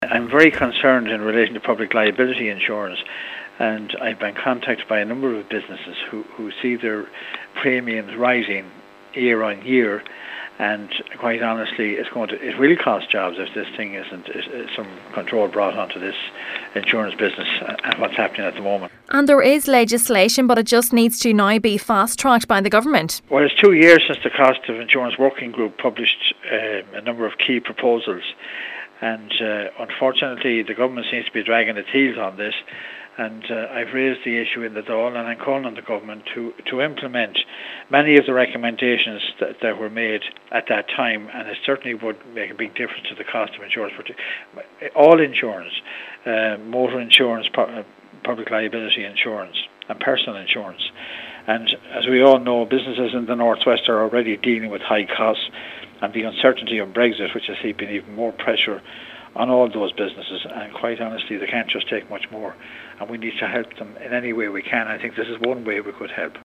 Deputy Scanlon says businesses in the North West are already dealing with high insurance costs and face more of the unknown with the uncertainty of Brexit: